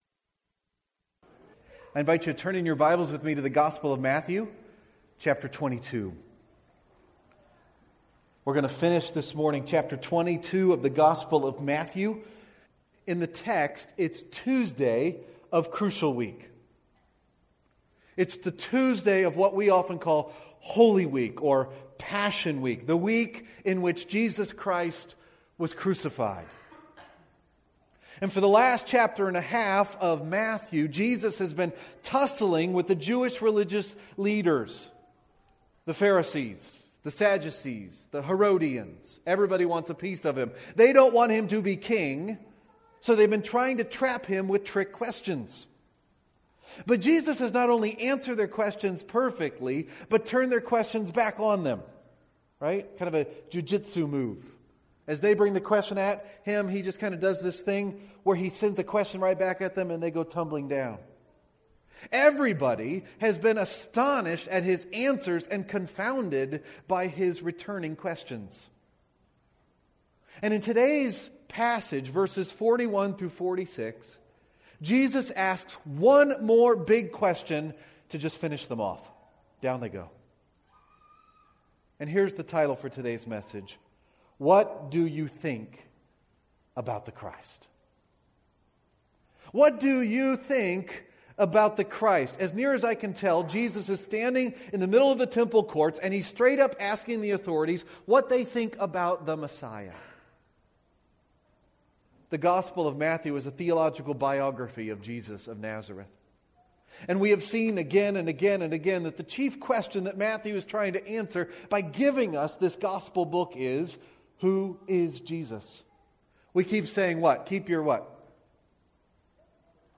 You can listen here or “Right-Click” and “Save-as” to download the sermon: What Do You Think About the Christ?